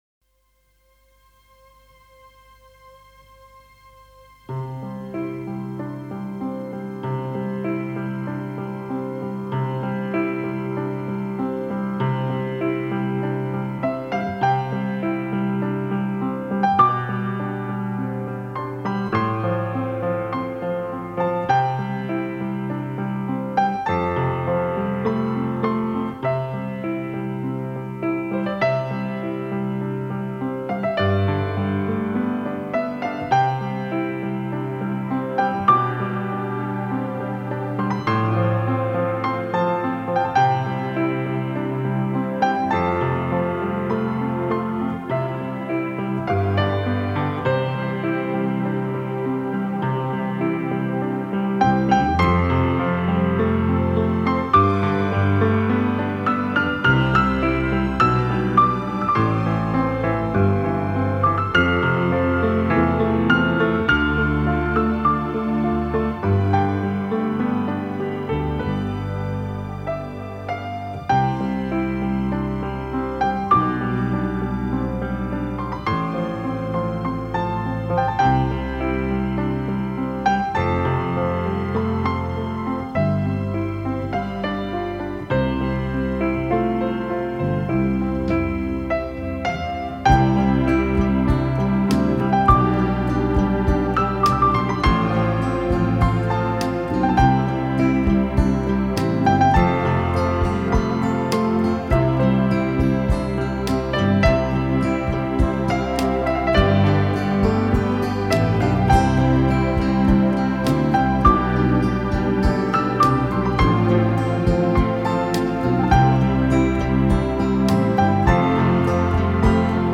无法仿效的钢琴演奏方式，绚烂的演出，